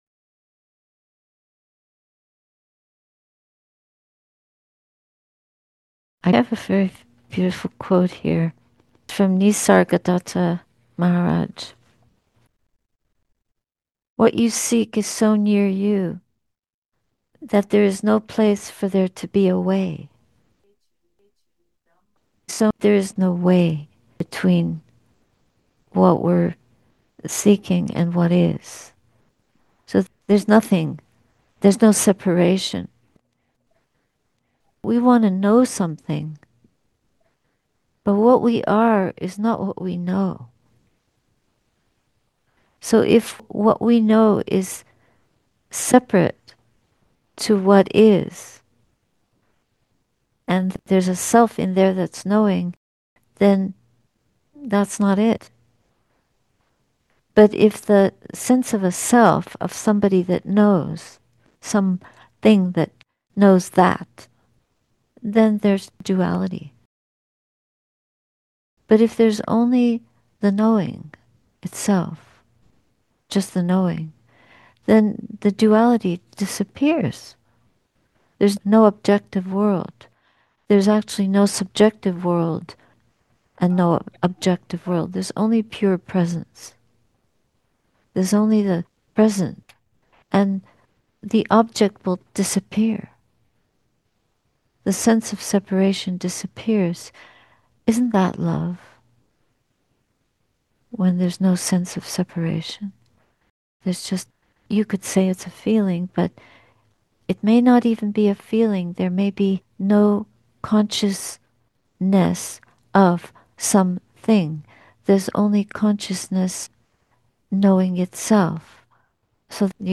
Theravada Buddhist Community, Toronto, May 25, 2025 https